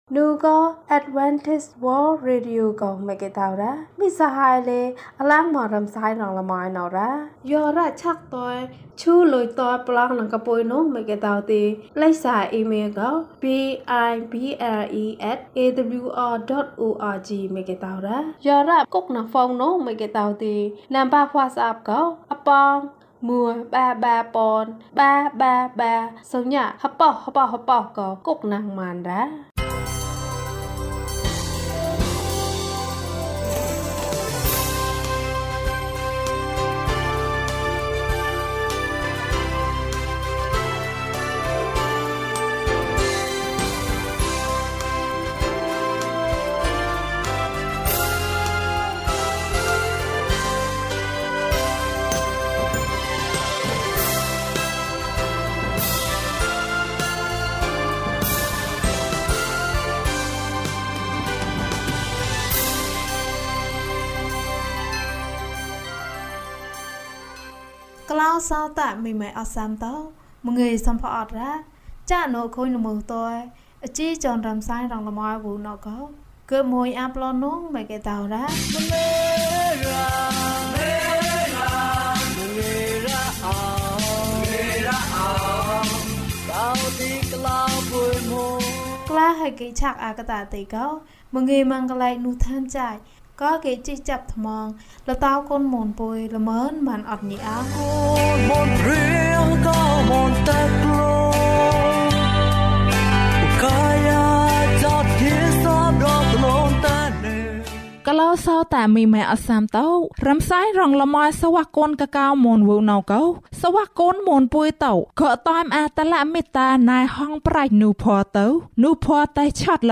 သူသည် ငါ၏ဘုရားသခင်ဖြစ်တော်မူ၏။ ကျန်းမာခြင်းအကြောင်းအရာ။ ဓမ္မသီချင်း။ တရားဒေသနာ။